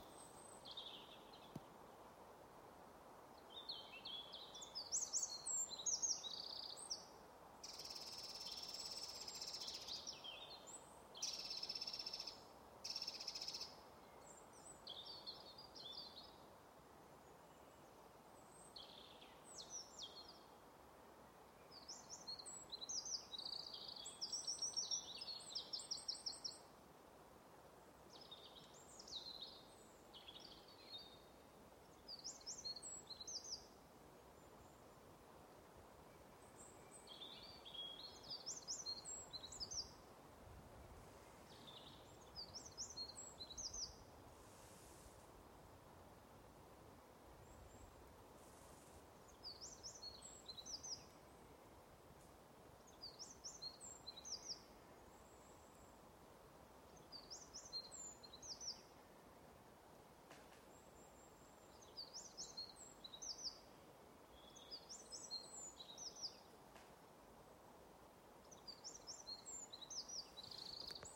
Paceplītis, Troglodytes troglodytes
Administratīvā teritorijaStrenču novads
StatussDzied ligzdošanai piemērotā biotopā (D)